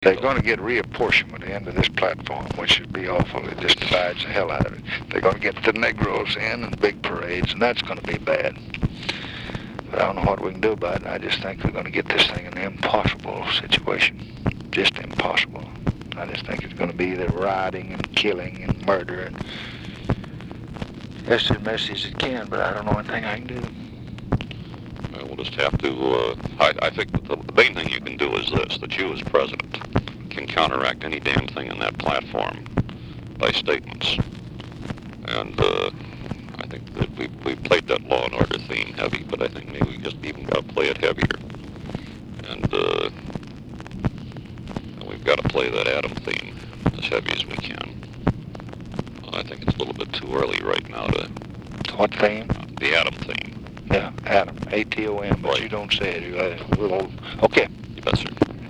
All of the President’s advisors understood that Goldwater’s vulnerability in his many reckless statements about the threat of nuclear war—and Johnson slyly made the point in this late August clip with press secretary George Reedy.